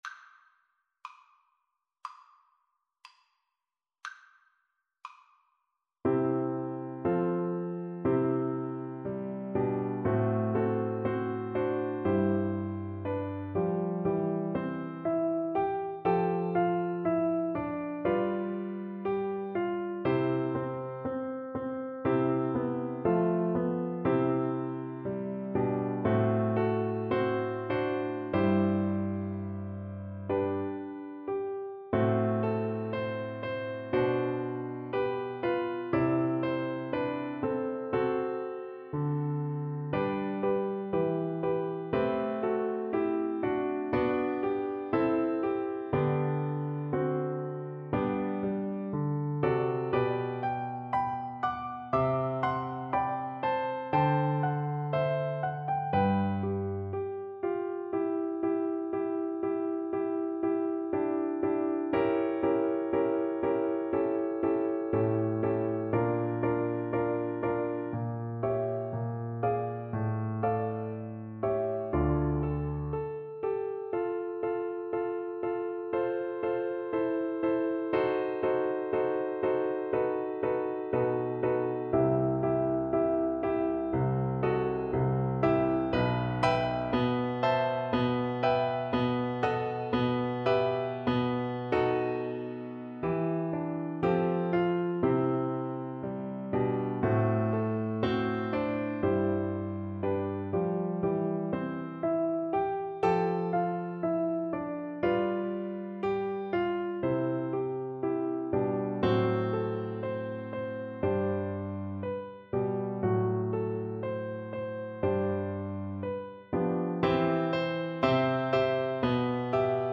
Play (or use space bar on your keyboard) Pause Music Playalong - Piano Accompaniment Playalong Band Accompaniment not yet available transpose reset tempo print settings full screen
French Horn
4/4 (View more 4/4 Music)
F#4-G5
C major (Sounding Pitch) G major (French Horn in F) (View more C major Music for French Horn )
Andantino =c.50 =60 (View more music marked Andantino)
Classical (View more Classical French Horn Music)